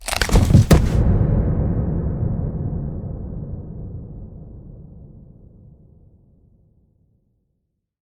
anonShooterFall.ogg